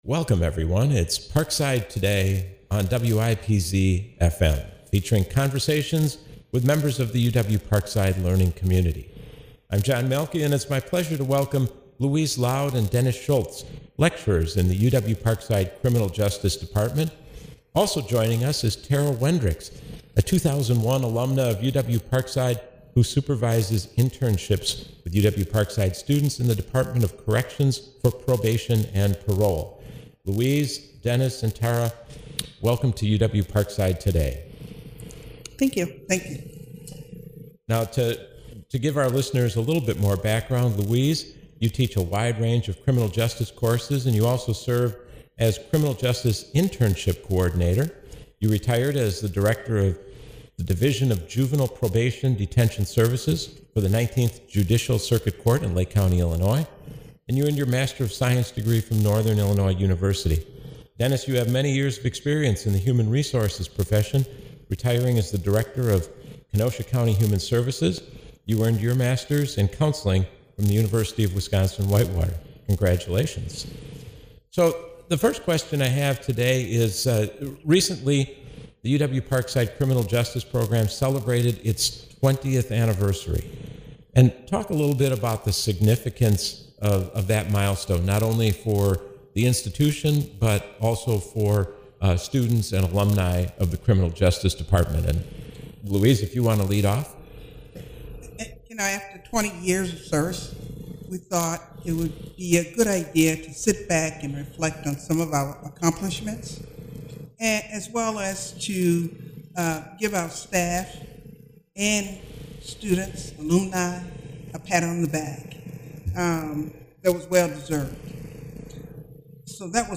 This show originally aired on May 8, at 4 p.m., on WIPZ 101.5 FM.